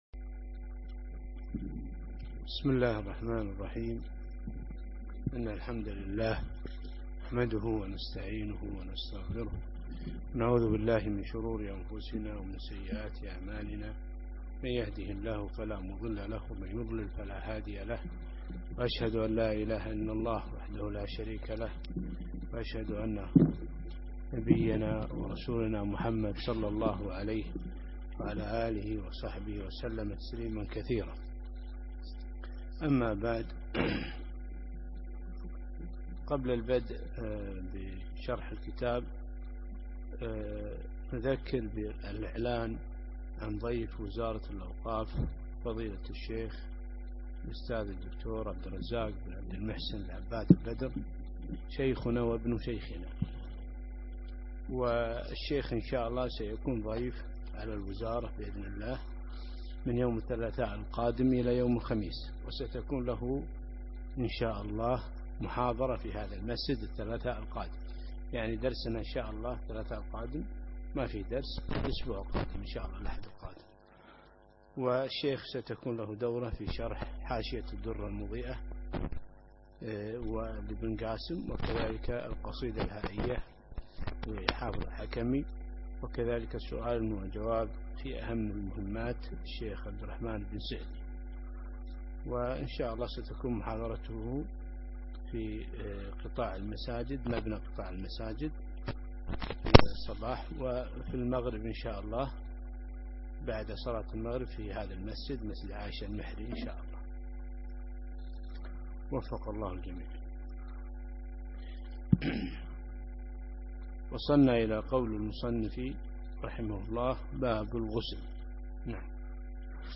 الدرس العاشر: باب الغسل